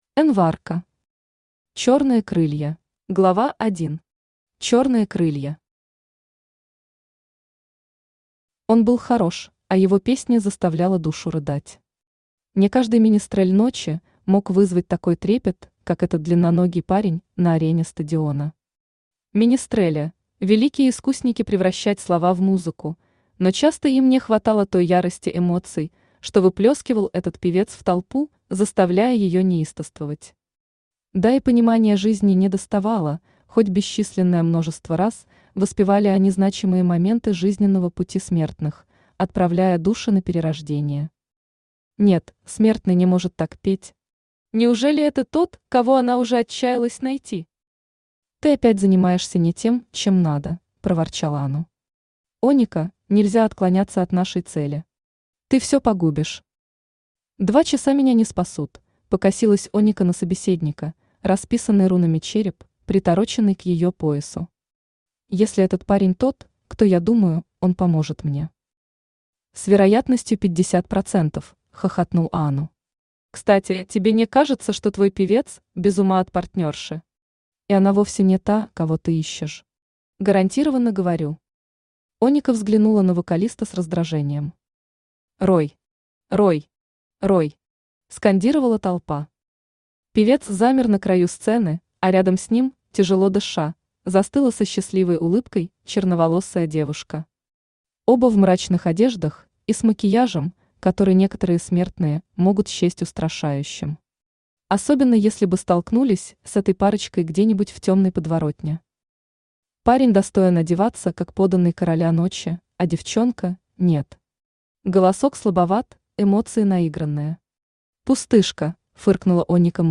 Аудиокнига Черные крылья | Библиотека аудиокниг
Aудиокнига Черные крылья Автор Эн Варко Читает аудиокнигу Авточтец ЛитРес.